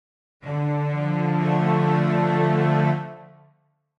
F Major Chord / Triad F minor chord / triad Click to hear a minor triad.
minor_chord.mp3